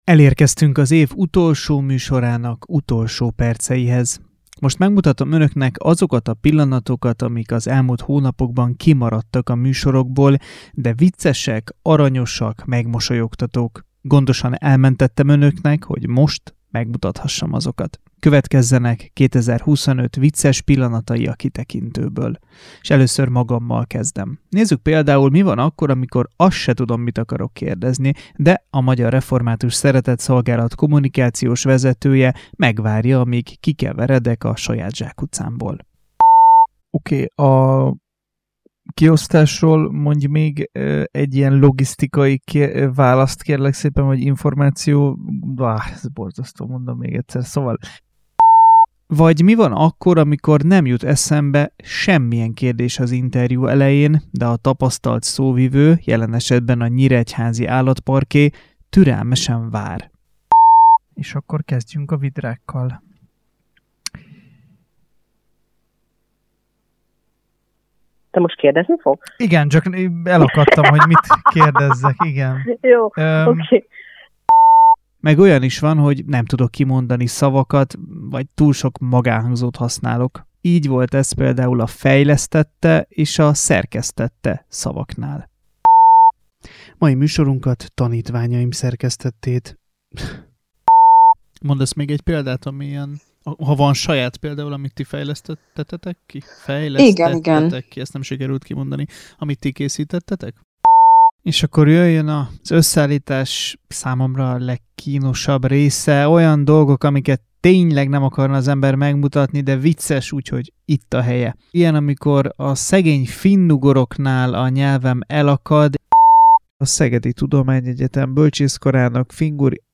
Íme 2025 vicces pillanatai, rádiós bakijai - avagy hogy kell kimondani, hogy fluoridos?
Most megmutatom Önöknek azokat a pillanatokat, amik az elmúlt hónapokban kimaradtak a műsorokból, de viccesek, aranyosak, megmosolyogtatók... gondosan elmentettem Önöknek, hogy most megmutathassam. Következzenek 2025 vicces pillanatai a Kitekintőből...